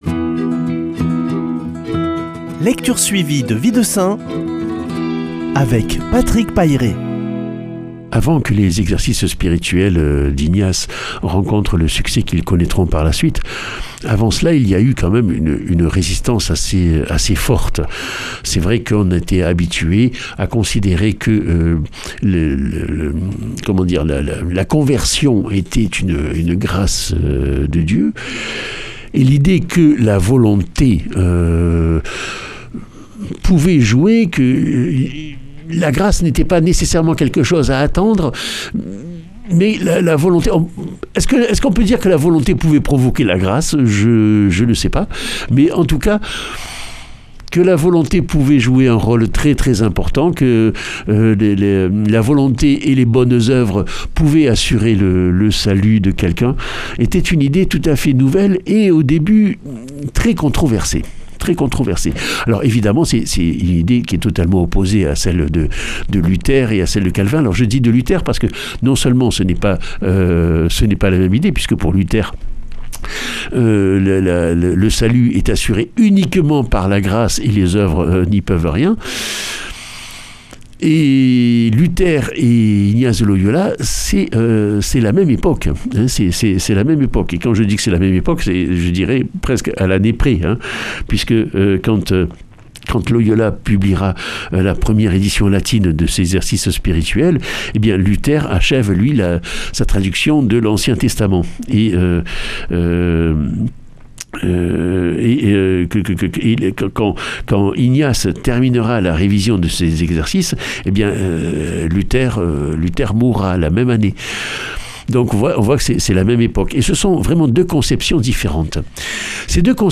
lundi 20 avril 2020 Récit de vie de saints Durée 2 min
Lecture suivie de la vie des saints